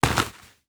Dirt footsteps 3.wav